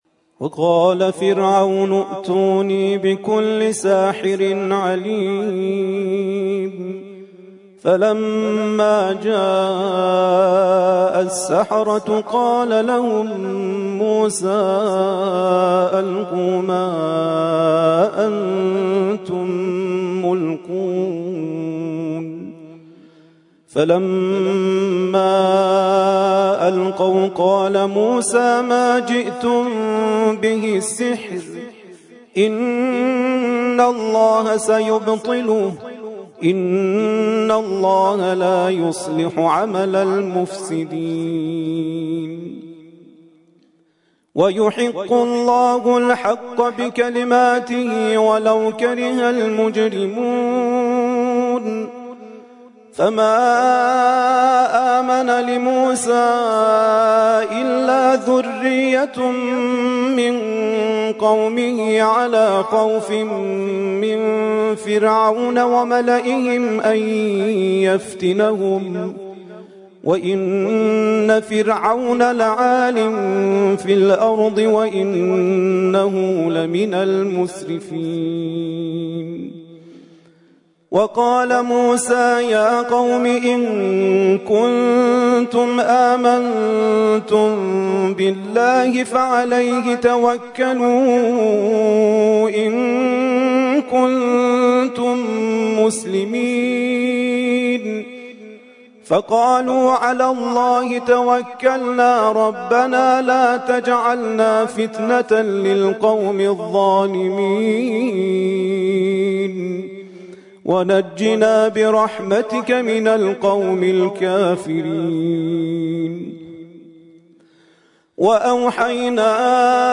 ترتیل خوانی جزء ۱۱ قرآن کریم - سال ۱۴۰۰